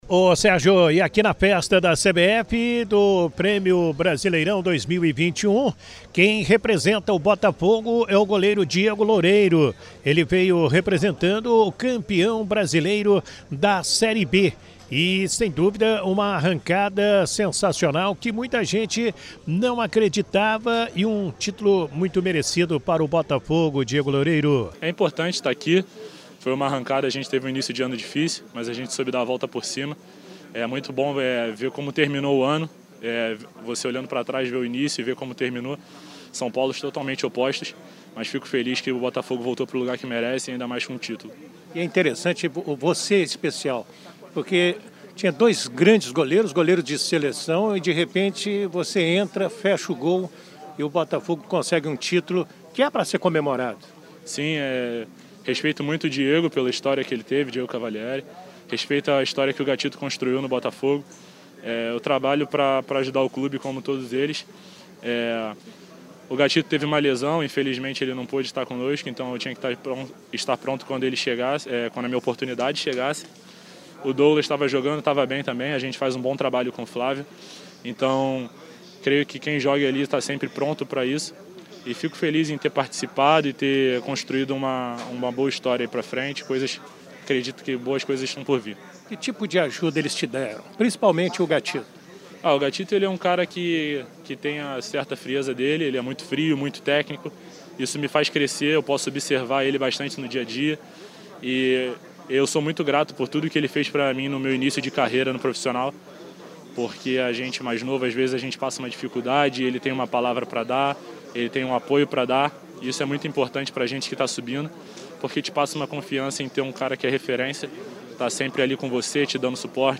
A Super Rádio Tupi marcou presença na entrega das premiações e conversou com alguns d